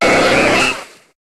Cri de Tentacool dans Pokémon HOME.